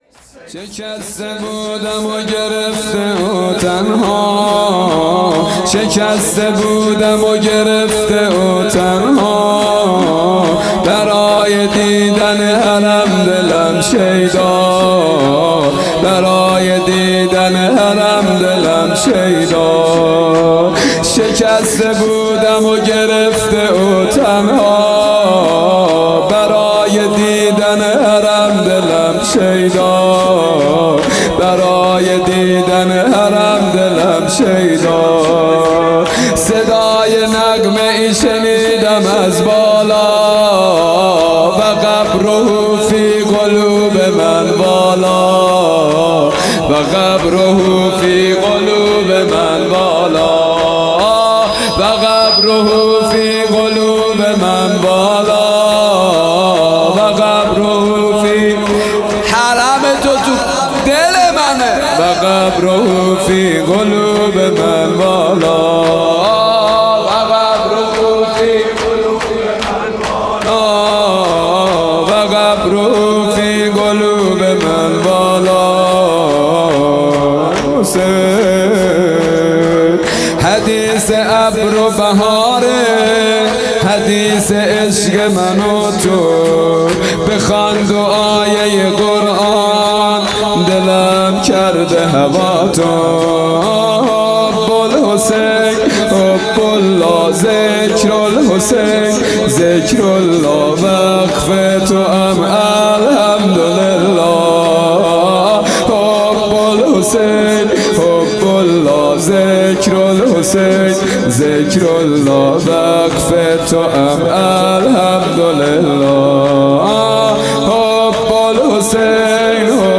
مراسم عزاداری پنج شب آخر صفر